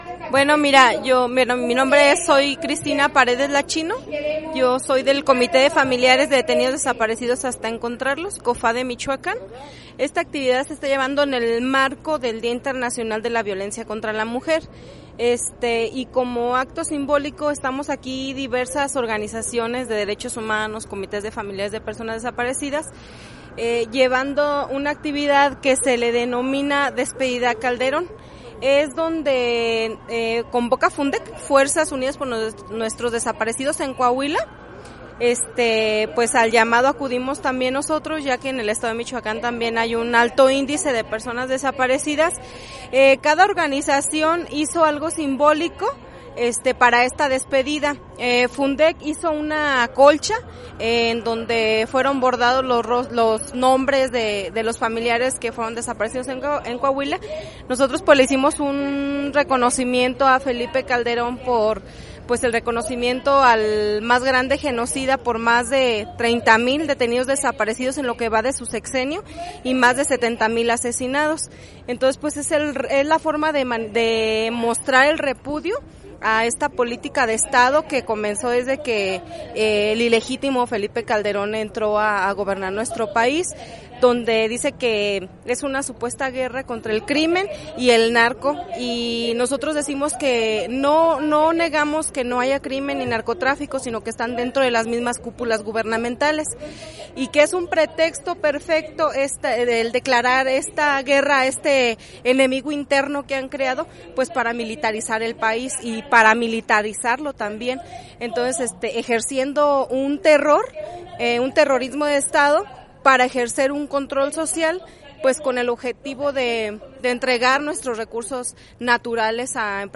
Por esto el día 25 de noviembre se realizó un mitin en la explanada de bellas artes a las 10 de la mañana donde participaron organizaciones como los compañeros del Comité de familiares de detenidos y desaparecidos hasta encontrarlos, Fuerzas Unidas por los desaparecidos hasta encontrarlos, Hijos México, entre otros; esta actividad se titulo "la despedida de Calderón" y por el 25 de noviembre "día internacional contra la violencia", fue una actividad de denuncia e informativa, se colocaron mantas, fotos e imágenes alucibas a la temática, además de mostrar una colcha bordada con nombres de los desaparecidos por parte de FUNDEC.
Te invitamos a que puedas escuchar la entrevista a los compañeros del Comité de familiares de detenidos y desaparecidos hasta encontrarlos:
Entrevista sobre la actividad